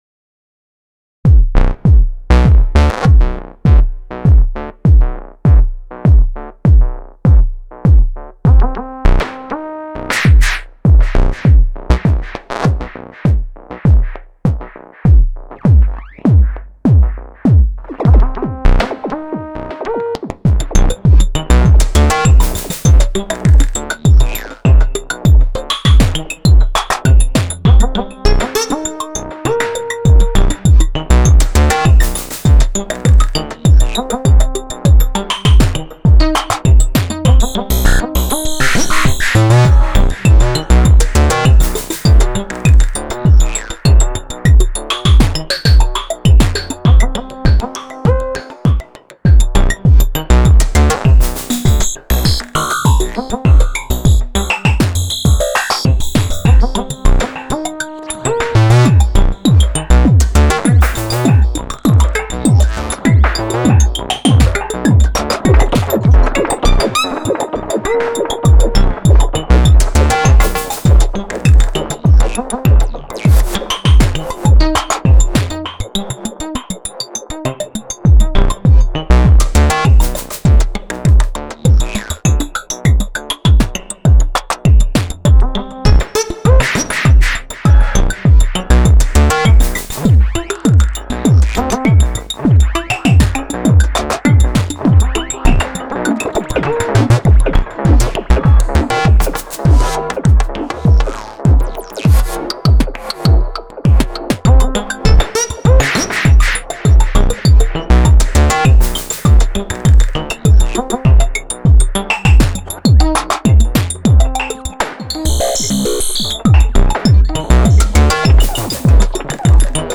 Jam 22. This is all Analog Four Mark II making use of performance macros, an Old Blood Noise Endeavors CV control slider, fills, bar-fills and plenty of p-locked Sound Pool trigs.